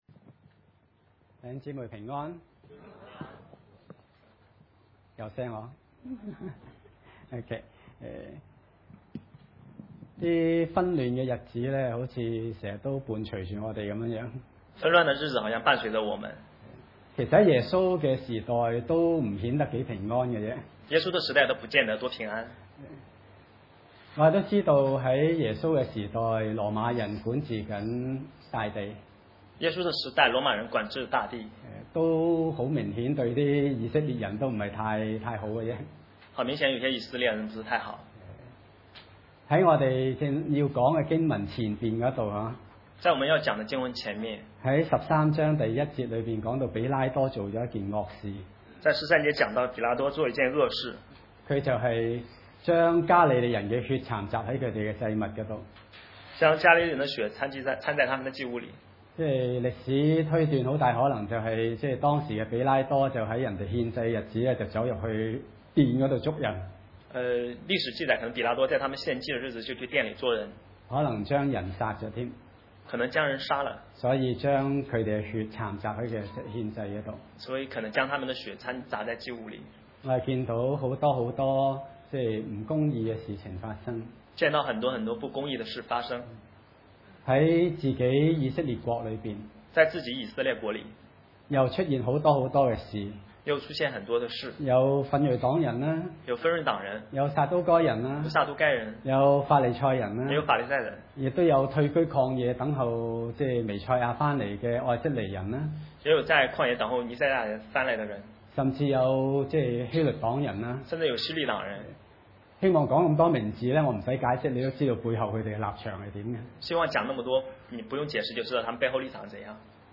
路十三10-21 崇拜類別: 主日午堂崇拜 10 安息日，耶穌在會堂裏教訓人。